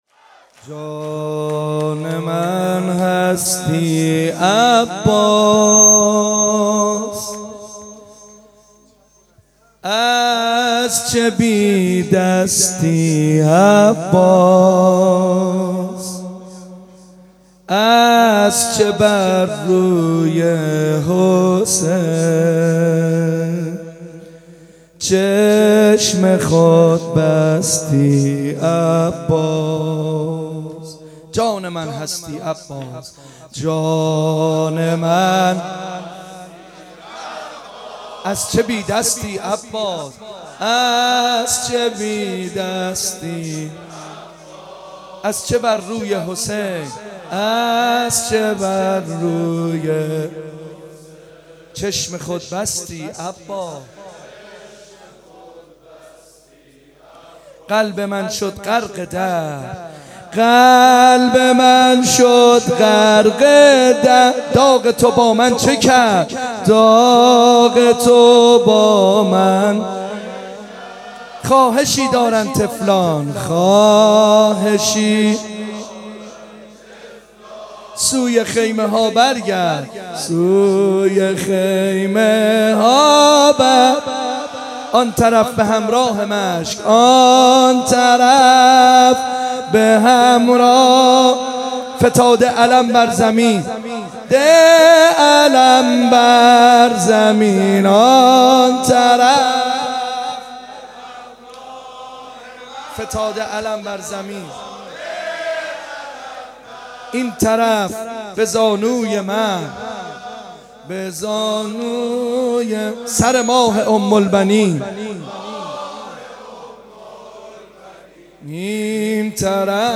مداحی
در مدرسه مبارکه فیضیه